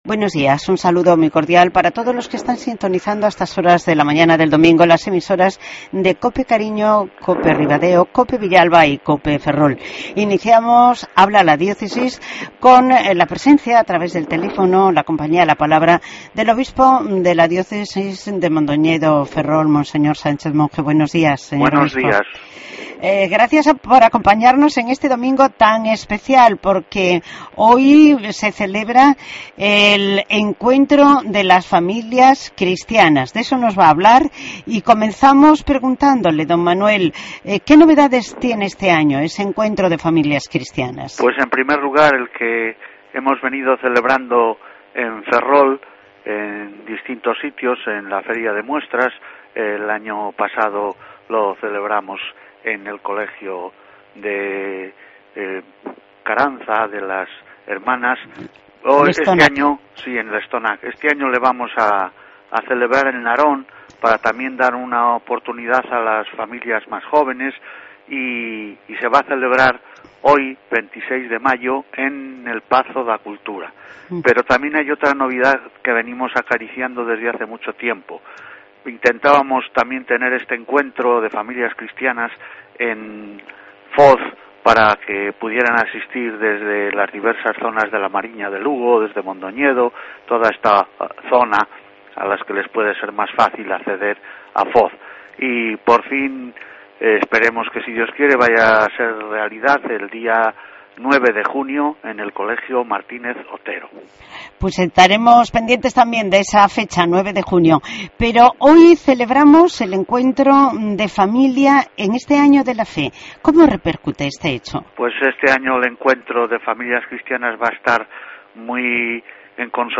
AUDIO: El obispo de nuestra Diócesis, Mons. Sánchez Monge, nos habla del Encuentro de Familias Cristianas.